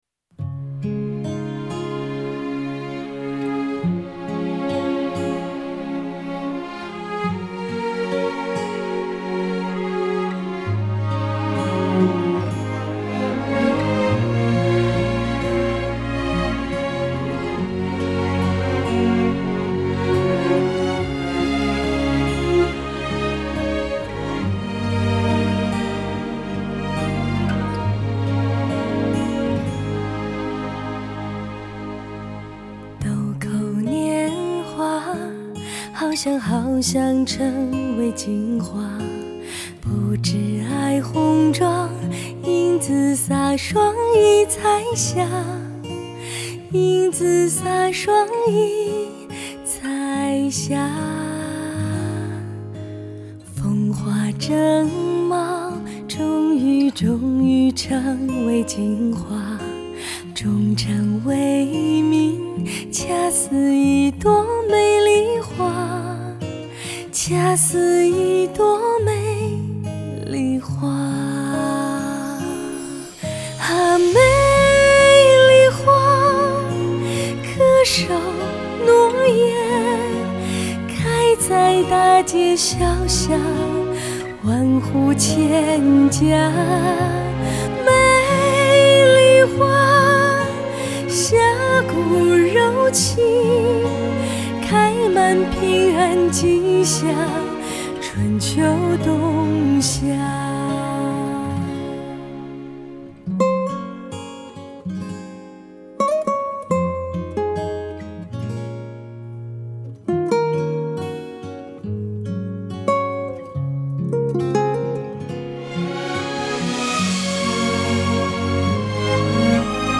旋律方面，曲作者用艺术歌曲的写法巧妙地融合了流行音乐元素